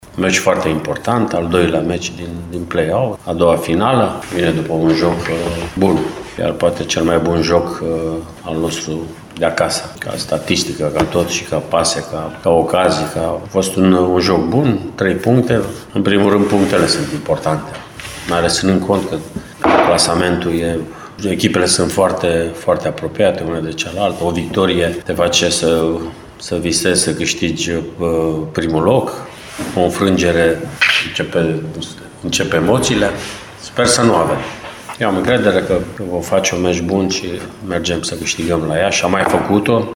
Antrenorul Mircea Rednic consideră extrem de important jocul din Copou și vorbește despre stilul impus de omologul său de la Iași, Vasile Miriuță: